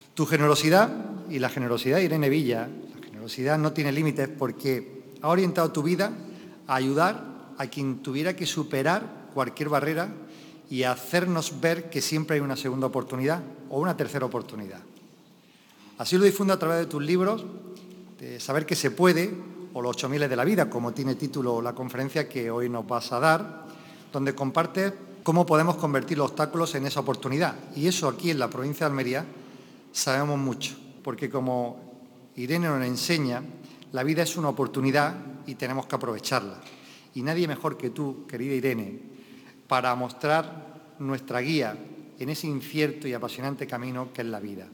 La periodista, psicóloga y escritora ha ofrecido una conferencia en el ciclo de Diputación ‘Mujeres y salud’ en la que ha compartido su experiencia personal para superar cualquier adversidad